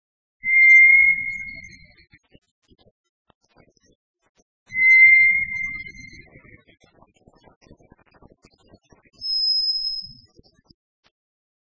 2125e「鳥の鳴声」
〔トラツグミ〕シー／クワックワッ／ヒー（さえずり）／（ビィヨー）／山地以下の繁